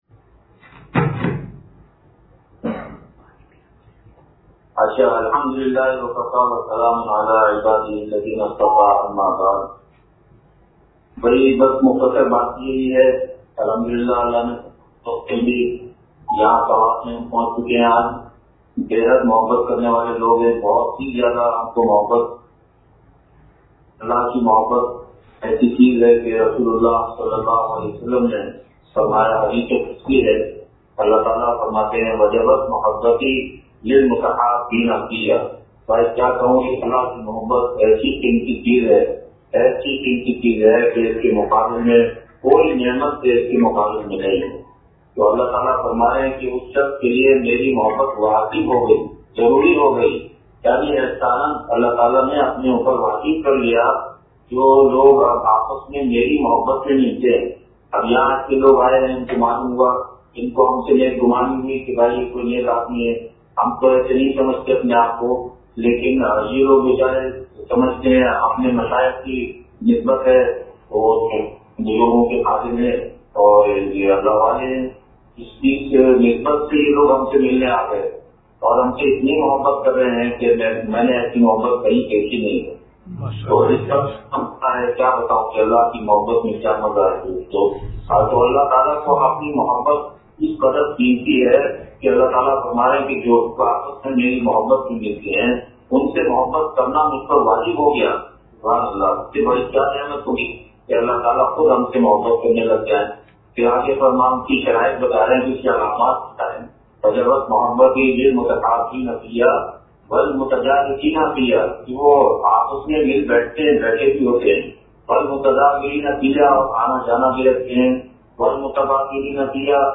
براہ راست بیان مینگورہ سوات